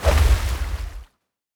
water sword Buff 5.wav